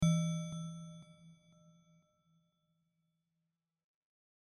bell_focus.mp3